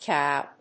/kaʊ(米国英語)/